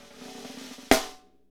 SNR FNK S0CL.wav